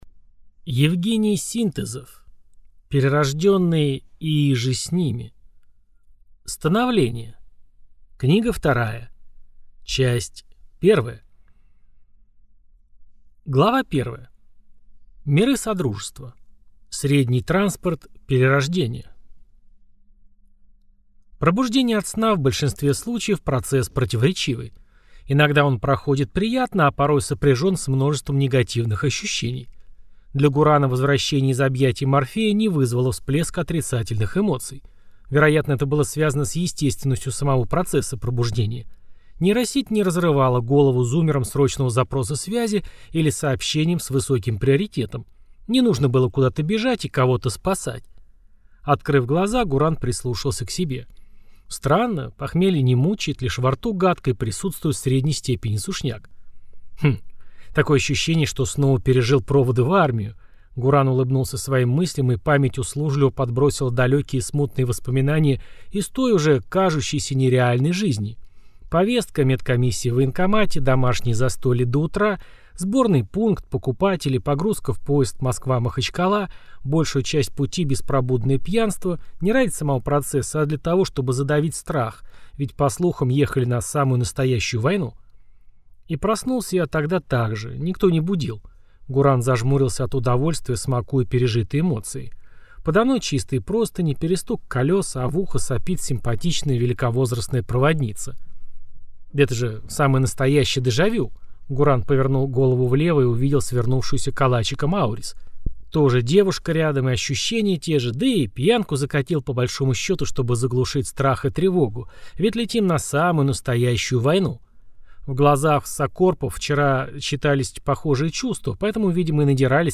Аудиокнига Перерожденные и иже с ними. Становление. Книга вторая. Часть 1 | Библиотека аудиокниг